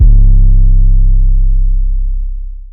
YM 808 3.wav